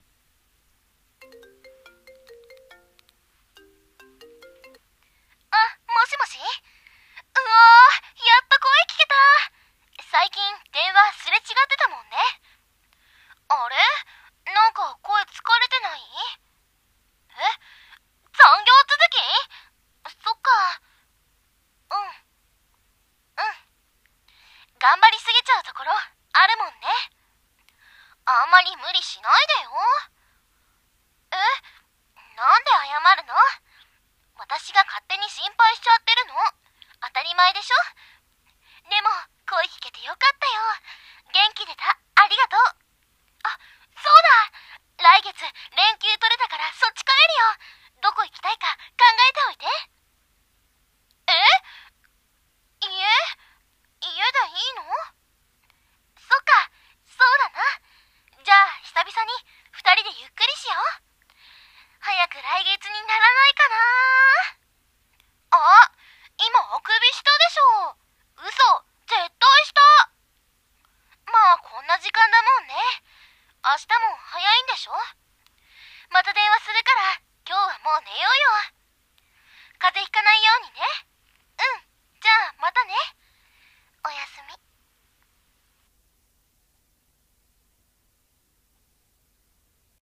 【電話声劇】遠距離中の恋人へ